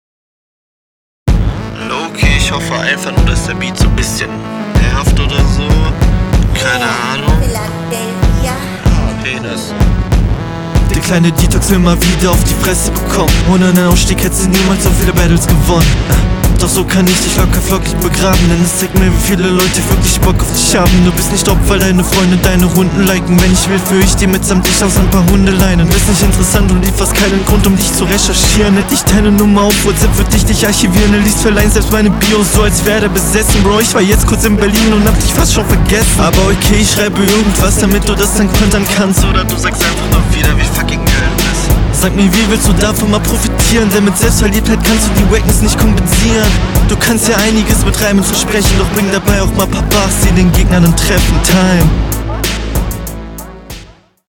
Verrückter Beat.